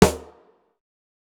TC SNARE 20.wav